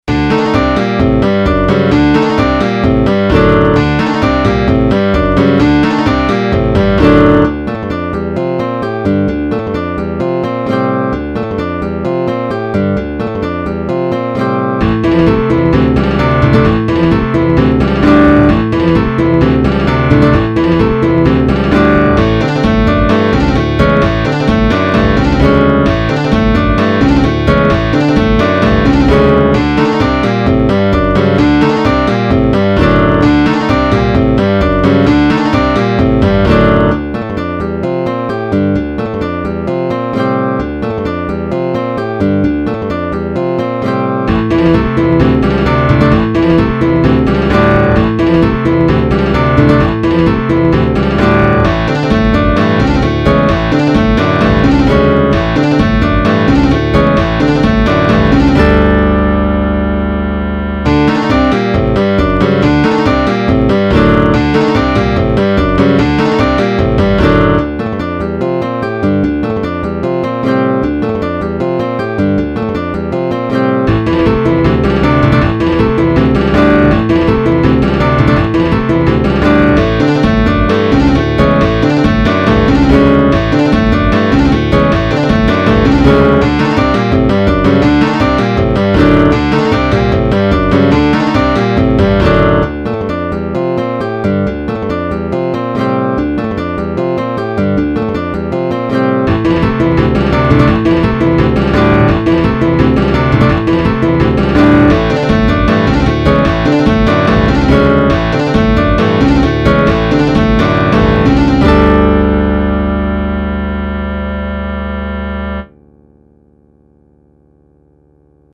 Ohmsägør, c'est une base metal ajoutant beaucoup d'éléments étrangers, du poprock au bebop en passant par le classique baroque, le death, le prog, la salsa.
La musique reste accessible mais particulièrement casse tête et technique, avec moults changements de tempo et harmonisations dangereusement...délicates, je dirais.
EDIT: J'ai rajouté une conversion midi en mp3, je sais que tout le monde n'a pas un midi génial donc ca donnera une idée un peu plus claire j'espère.